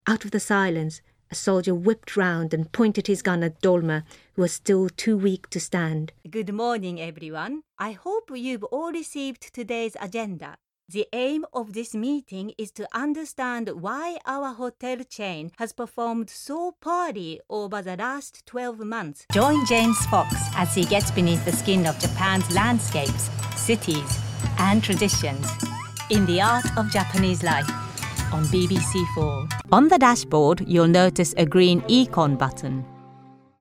Japanese, Female, Home Studio, 20s-50s
Home Studio Read